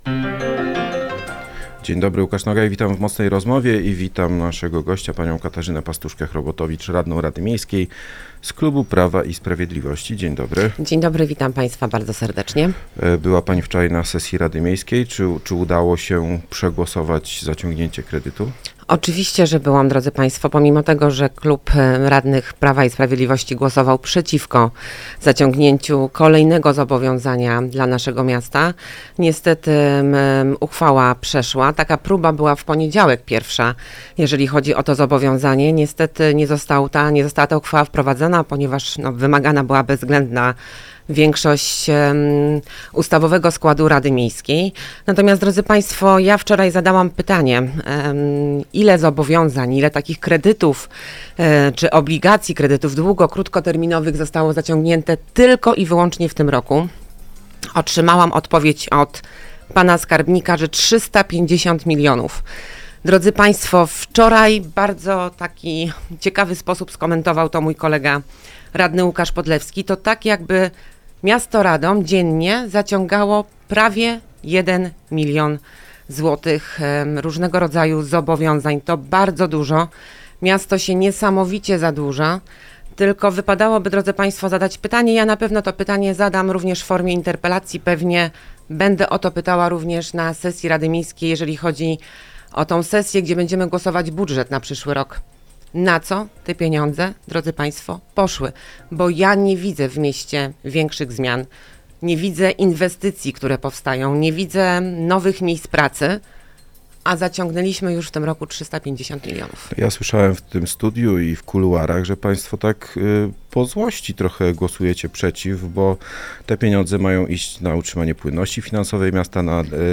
Katarzyna Pastuszka-Chrobotowicz, radna Prawa i Sprawiedliwości była gościem